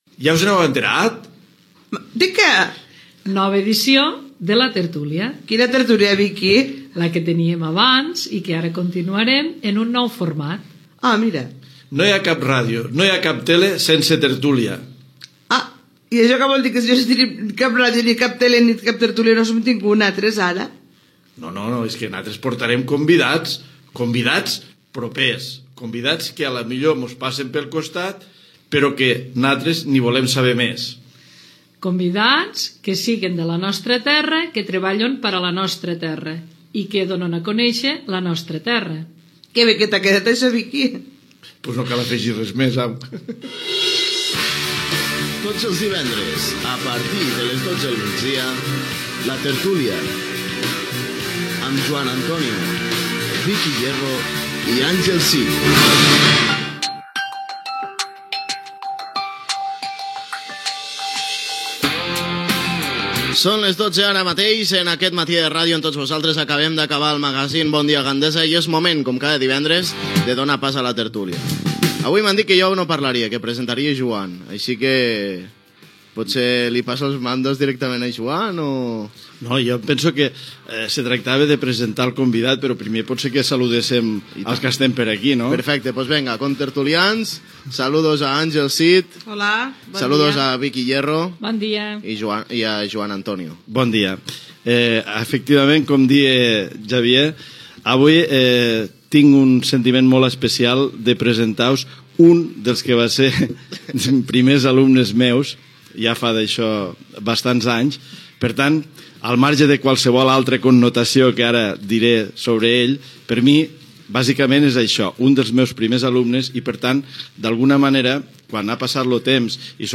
Ràdio Gandesa - La tertúlia de Ràdio Gandesa
Presentació, careta del programa, horari, inici de la tertúlia